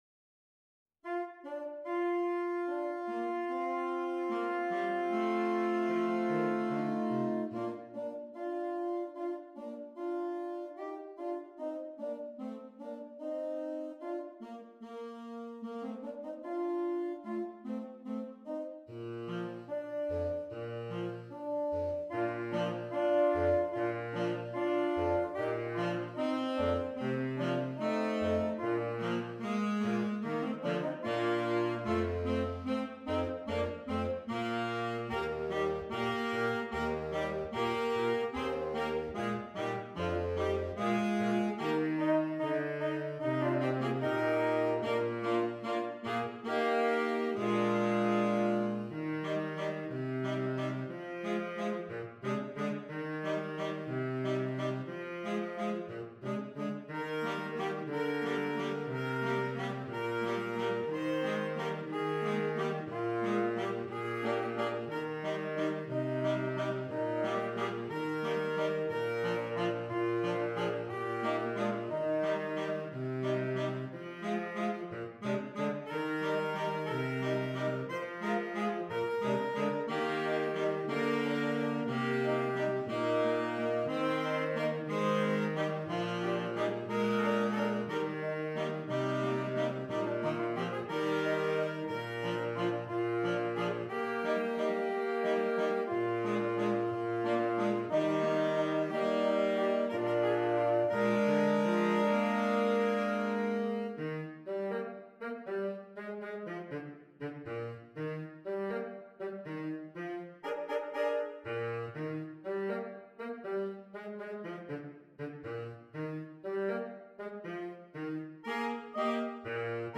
Saxophone Quartet (AATB)
Traditional